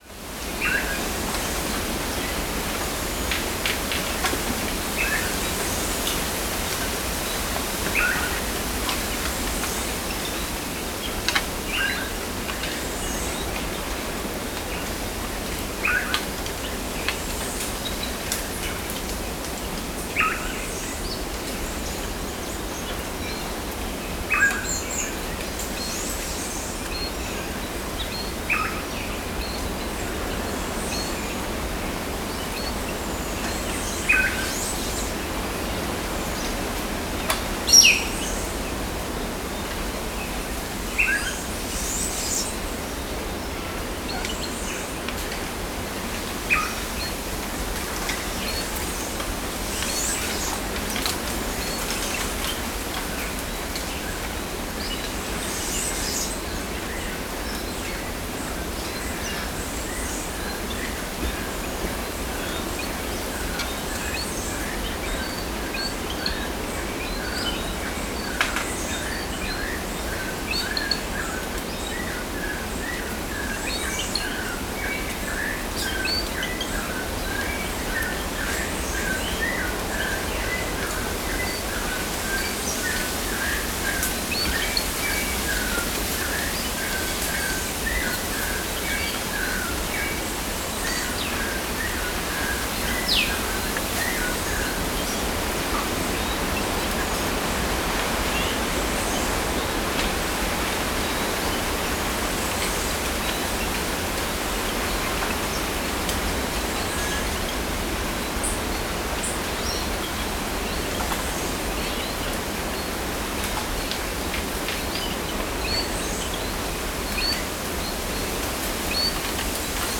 Bamboo_forest.R.wav